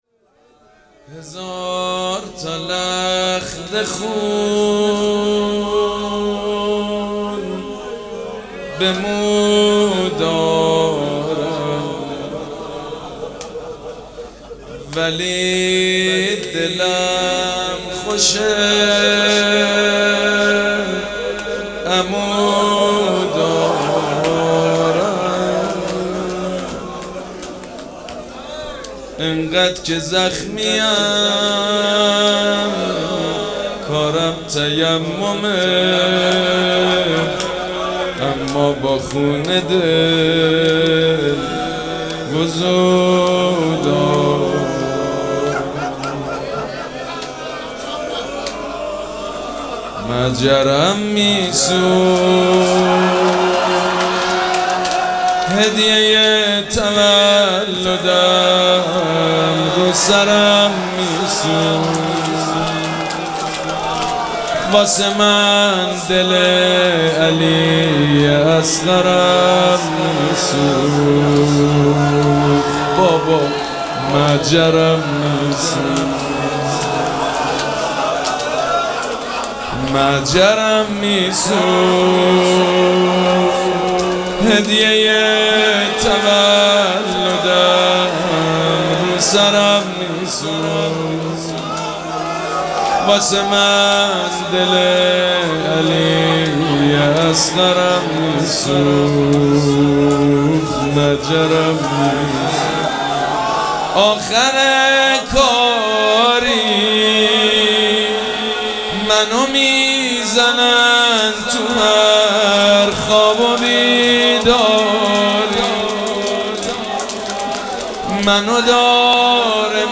مراسم عزاداری شام شهادت حضرت رقیه سلام الله علیها
روضه
حاج سید مجید بنی فاطمه